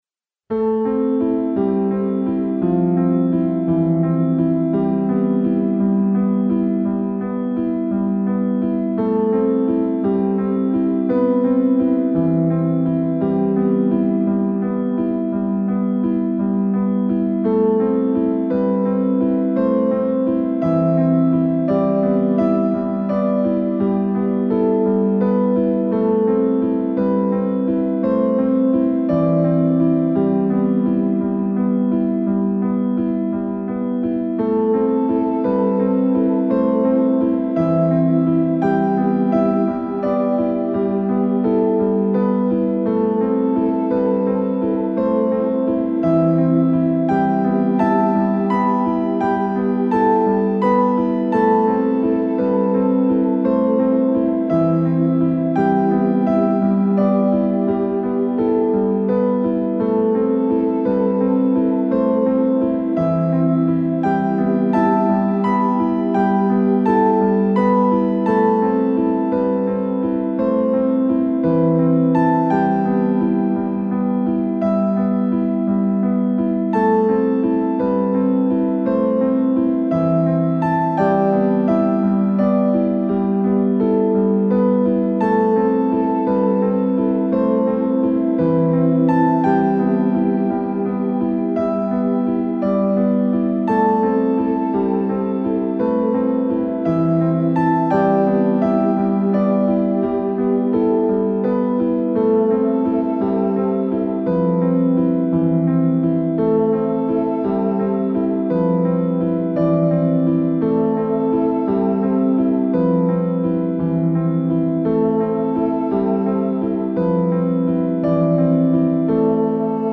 Valse Triste